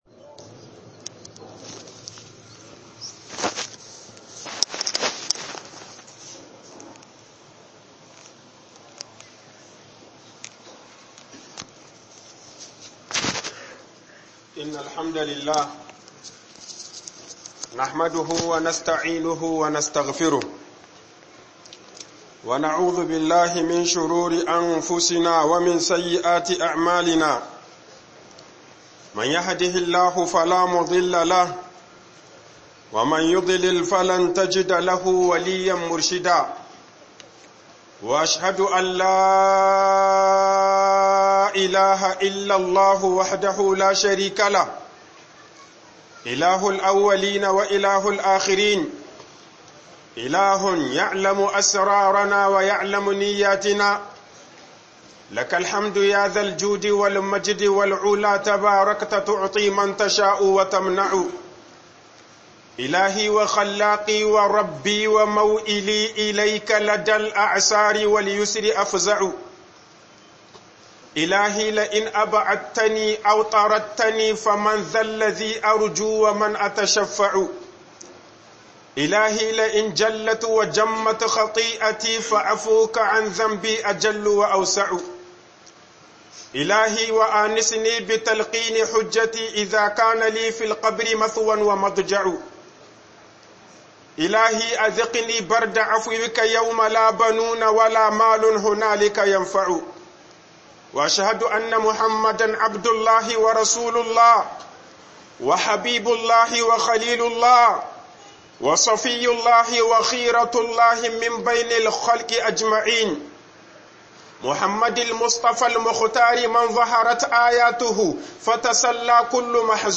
AUD-Wanene dan ta adda - HUƊUBOBIN JUMA'A